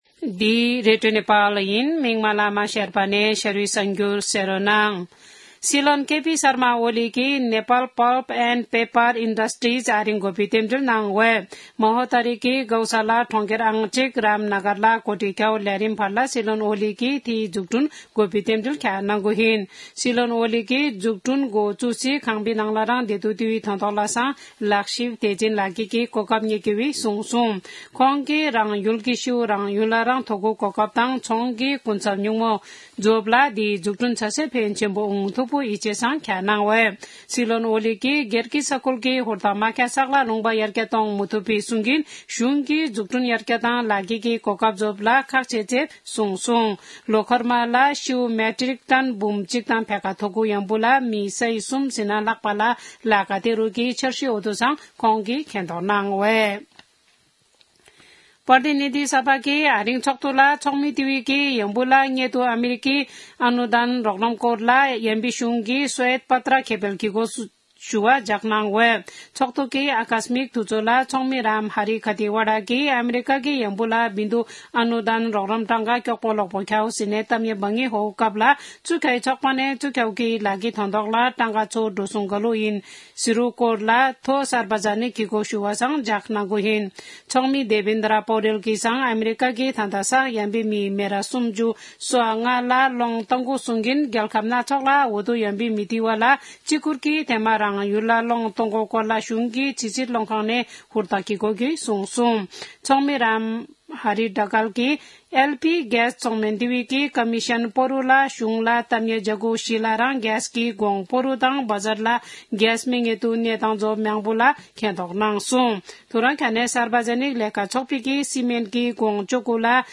शेर्पा भाषाको समाचार : २३ फागुन , २०८१
sharpa-news-1-3.mp3